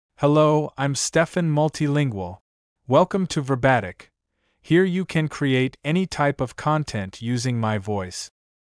MaleEnglish (United States)
Steffan Multilingual is a male AI voice for English (United States).
Voice sample
Listen to Steffan Multilingual's male English voice.
Steffan Multilingual delivers clear pronunciation with authentic United States English intonation, making your content sound professionally produced.